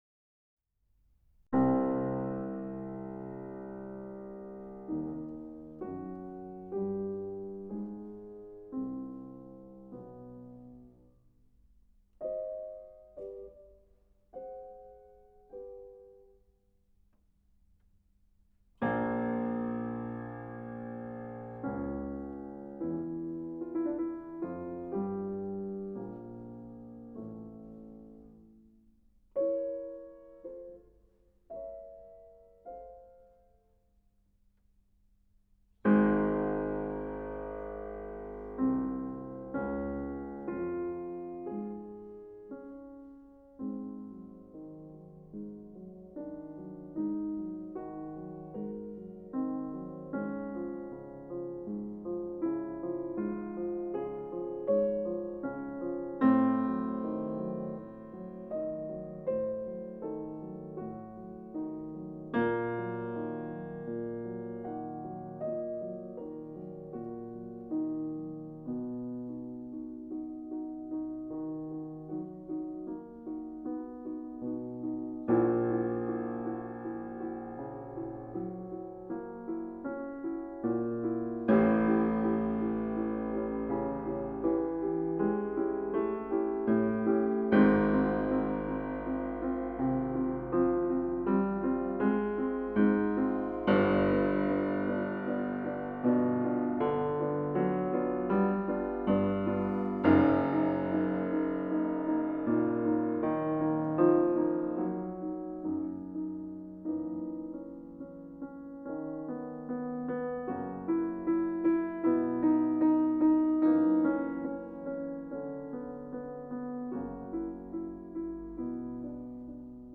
Fantasia 4 for piano in Cm, K.475
Fantasia 4 for piano in Cm, K.475.mp3